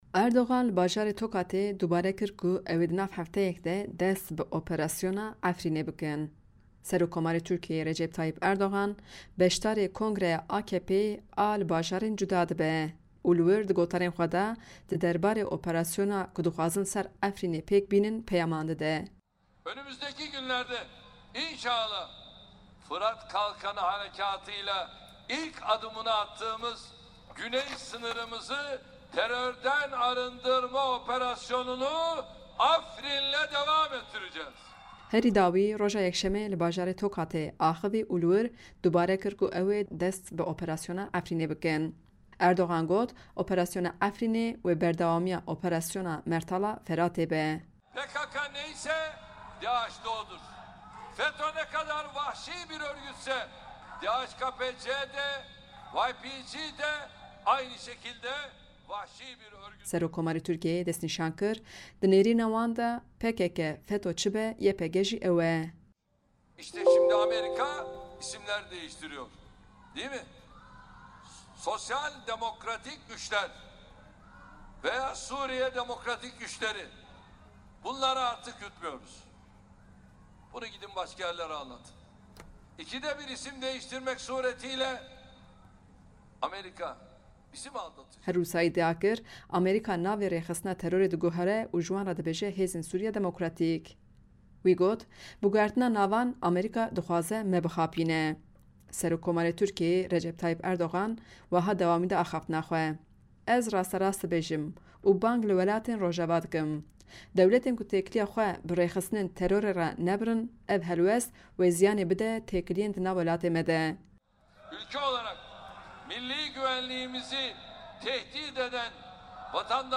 Erdogan Yekşemê di girseya kongira herêmî ya partiya AKP'ê de, li bajarê Tokatê dubare kir û got, ewê di demek nêzîk de, dest bi operasyona Efrînê bikin.
Gotinên Erdogan di fayla deng de gûhdarî bikin.